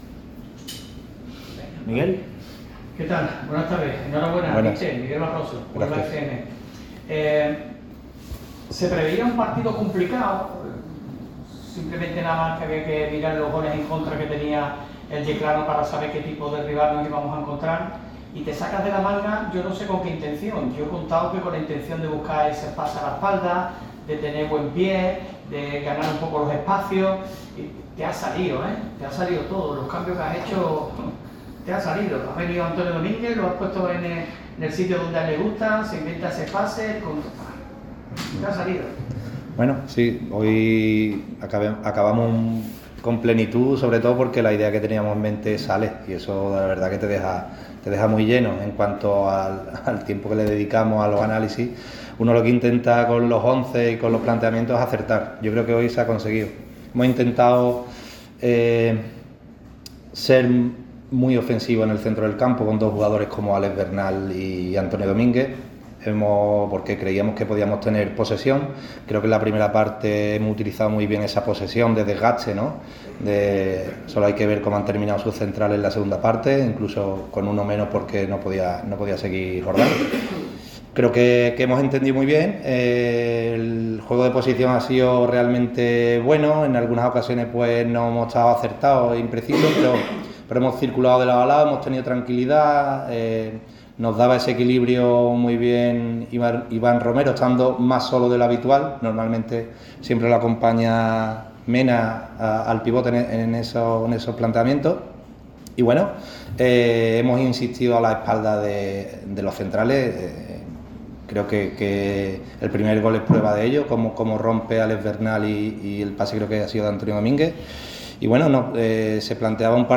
Puedes oir la rueda de prensa íntegra en este reproductor: